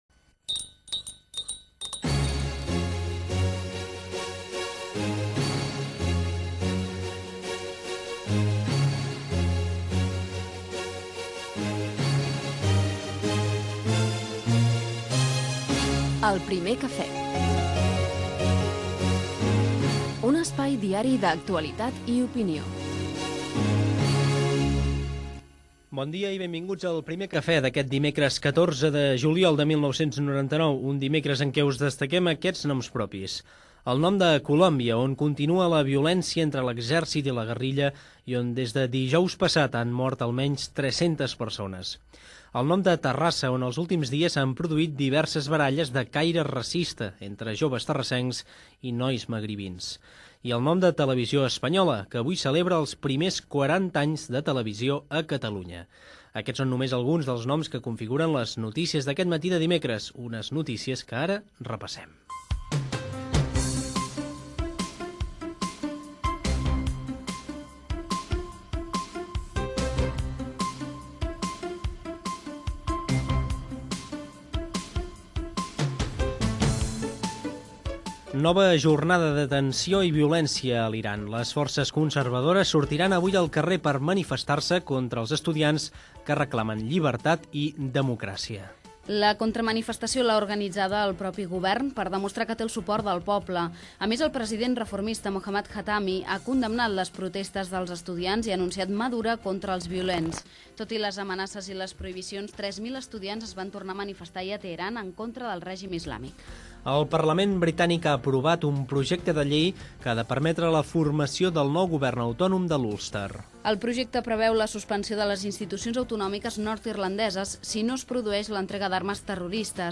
62df1f37884c99b19a48534cd16f541b551772c6.mp3 Títol Ràdio Estel Emissora Ràdio Estel Cadena Ràdio Estel Titularitat Privada nacional Nom programa El primer cafè de Ràdio Estel Descripció Careta del programa, data, els noms destacats del dia, resum informatiu (Iran, Parlament Britànic, Colòmbia, pactes polítics a l'Estat, pactes polítics a les illes Balears i a Melilla, etc.), hora, temperatura, el temps, publicitat, el trànsit des del RACC, publicitat, el trànsit a Barcelona i indicatiu del programa. Gènere radiofònic Informatiu